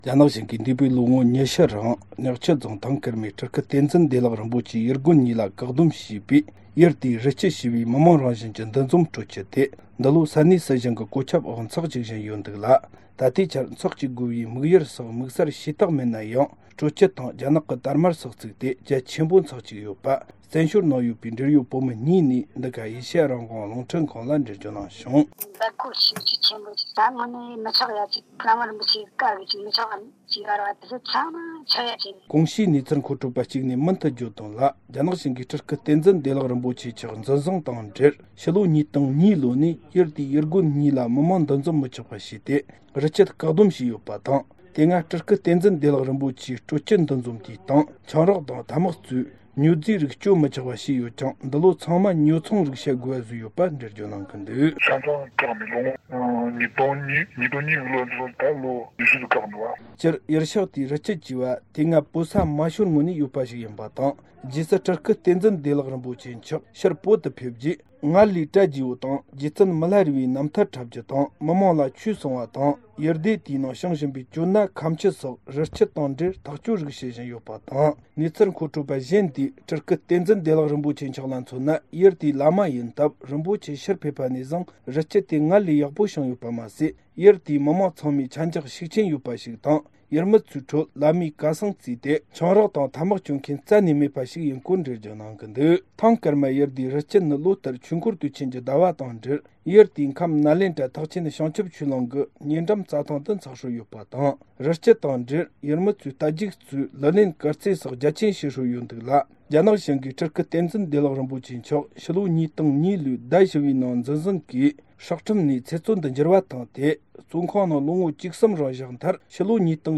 སྒྲ་ལྡན་གསར་འགྱུར། སྒྲ་ཕབ་ལེན།
བཙན་བྱོལ་ནང་ཡོད་པའི་འབྲེལ་ཡོད་བོད་མི་གཉིས་ནས་འགྲེལ་བརྗོད་གནང་བྱུང་།